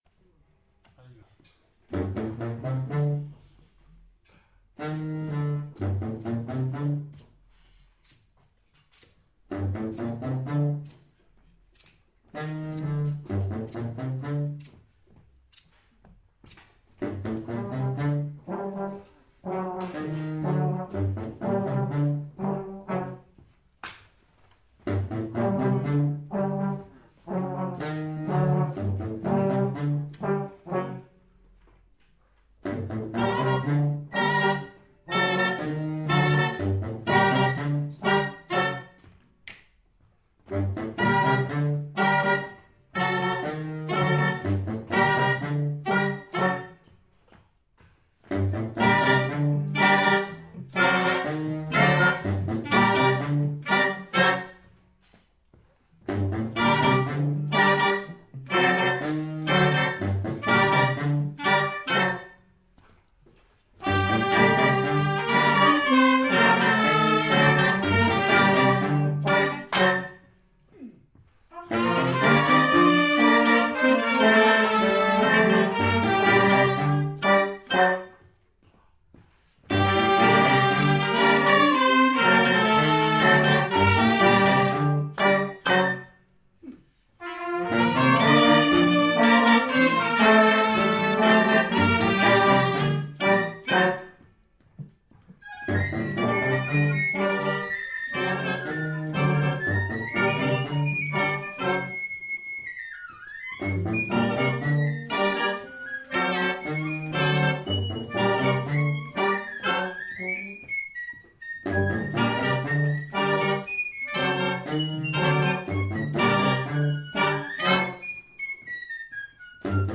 Enregistrements Audio lors du 1er stage 2014
(brouillon d’enregistrement de travail)